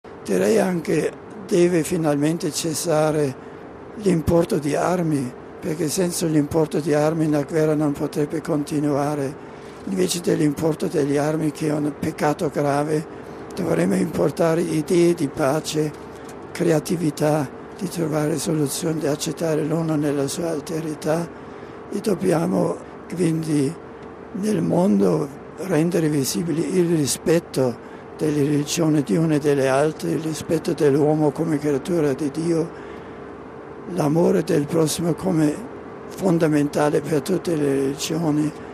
In this direction, Benedict indicates when answering Questions from journalists during the trip to Lebanon on September 14, 2012.